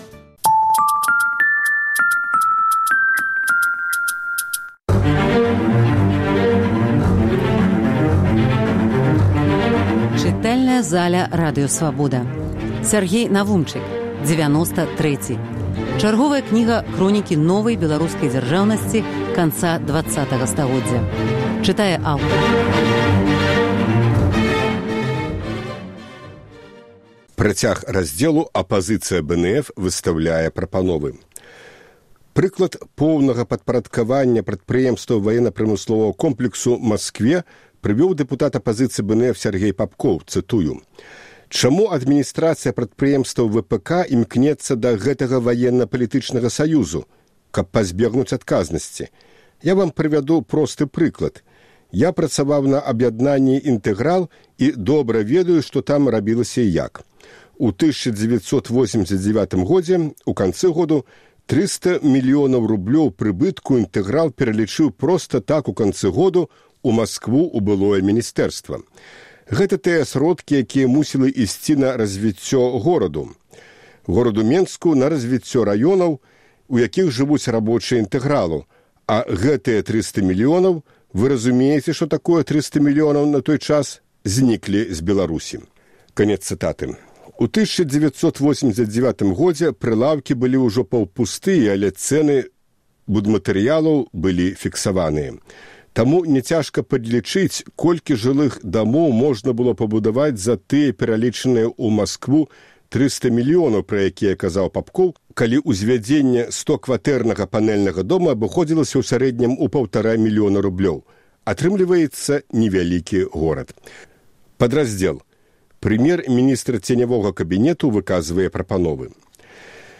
Фрагмэнты кнігі Сяргея Навумчыка "Дзевяноста трэці". Чытае аўтар